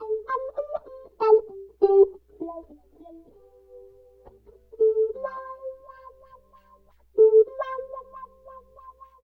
88 GTR 2  -R.wav